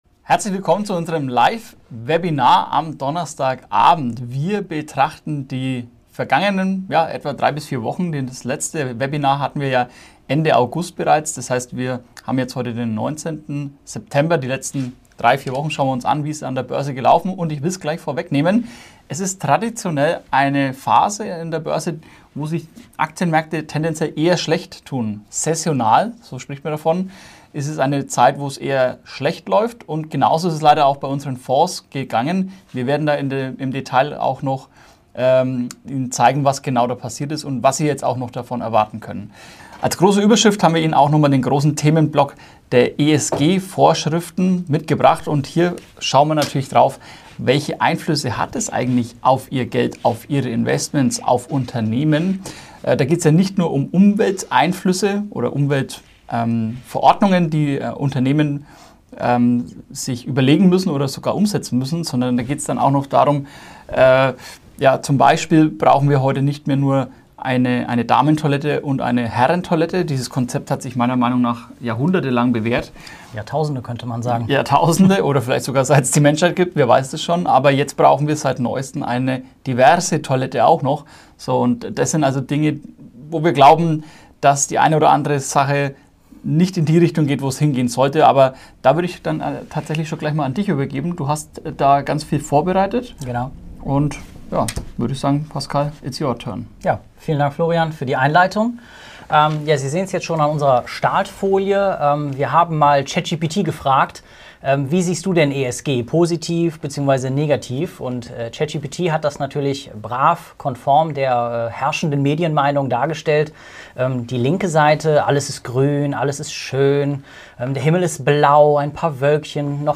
Live Webinar